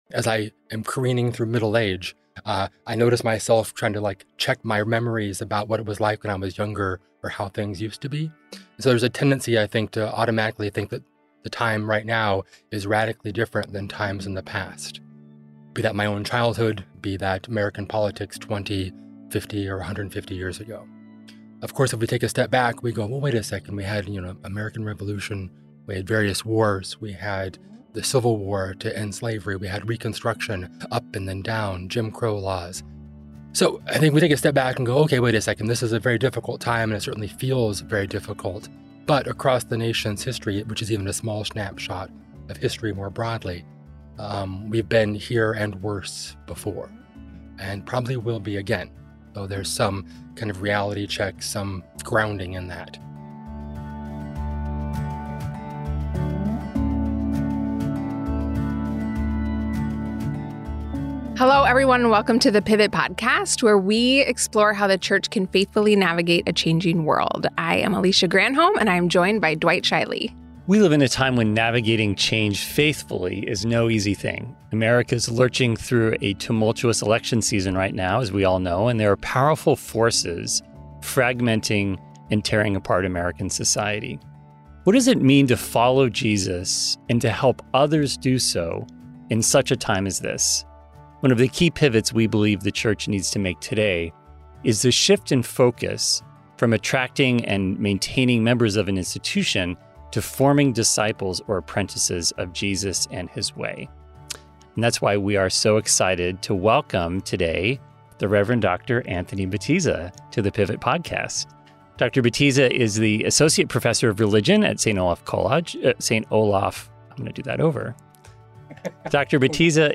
Join us for a conversation that will challenge and inspire you to approach politics with both biblical wisdom and neighborly love.